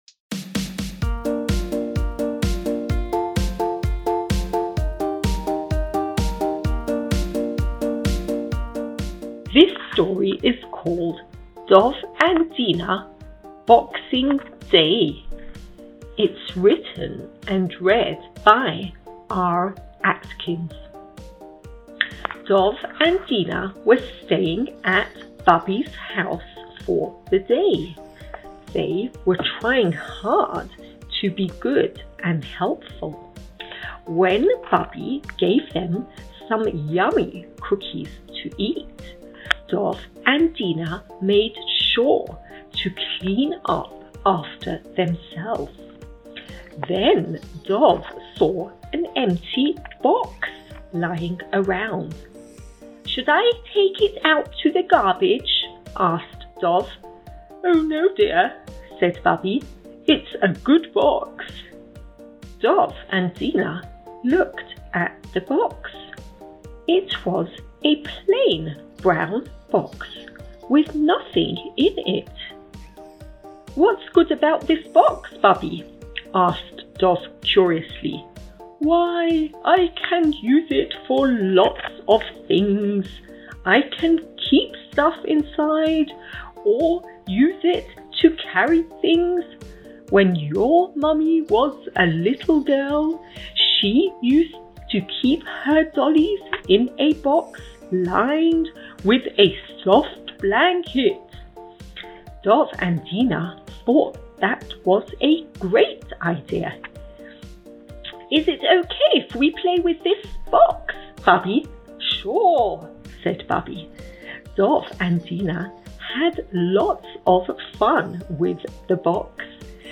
| Read-Along Storytime |